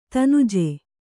♪ tanuje